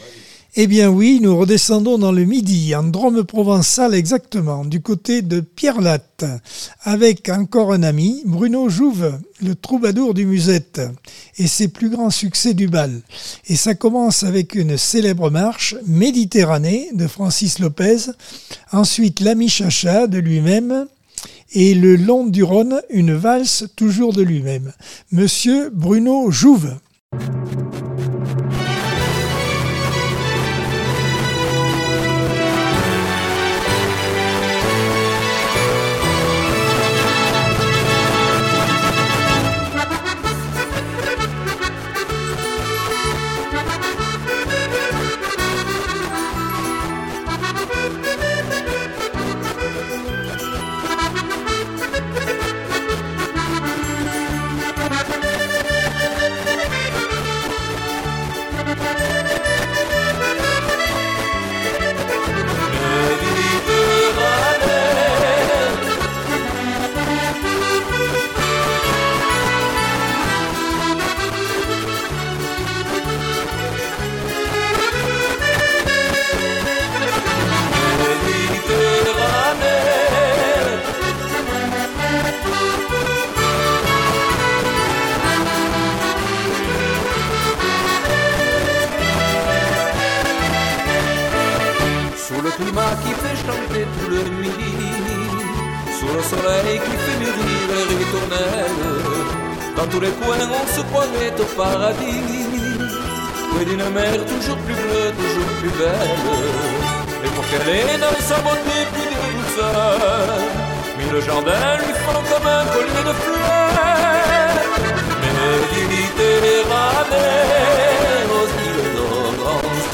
3 Jeudi par Accordeon 2023 sem 23 bloc 3.